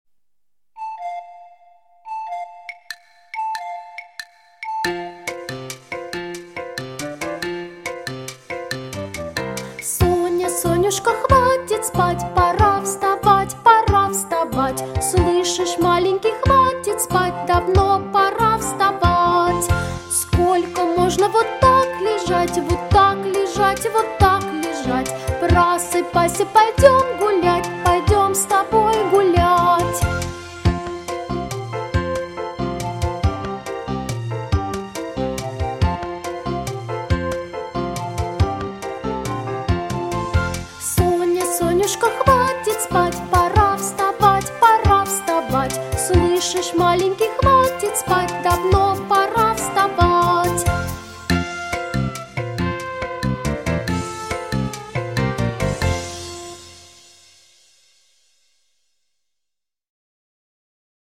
• Категория: Детские песни
распевка